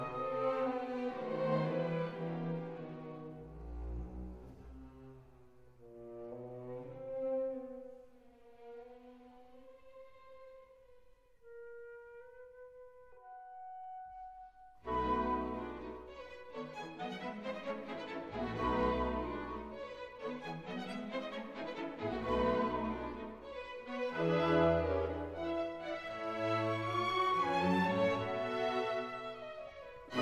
"templateExpression" => "Musique orchestrale"